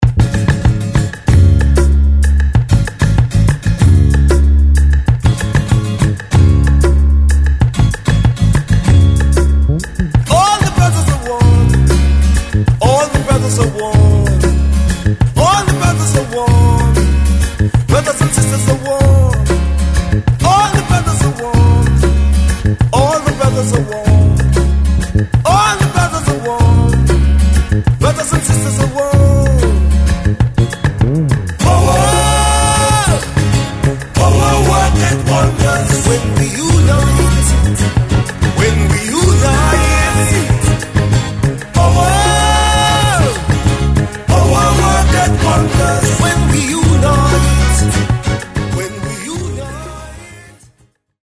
With an Eclectic Roots Rock Reggae!